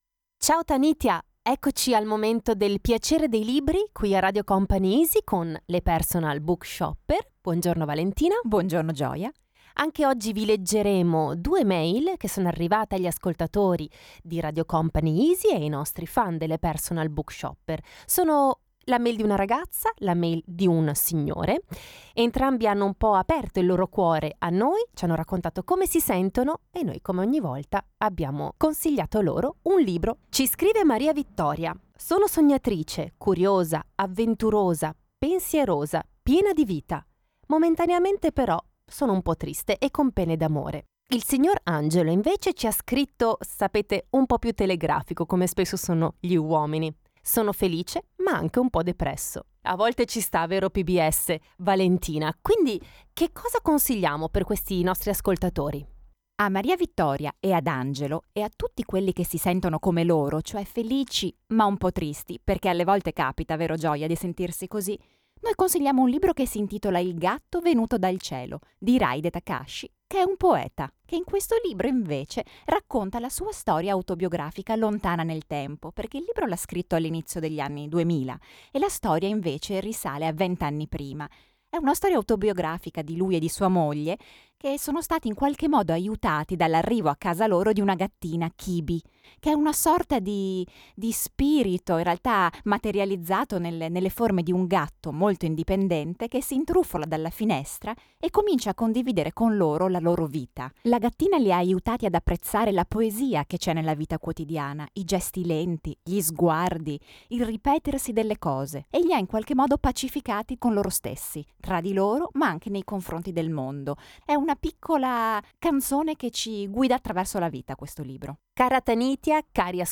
Perciò presentiamo a Radio Company Easy la demo di una rubrica dedicata al piacere della lettura: